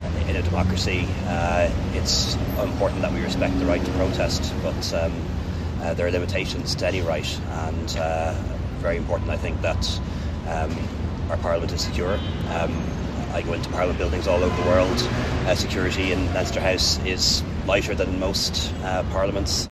Leo Varadkar says the right to protest must be balanced with the security concerns of those who work in the building: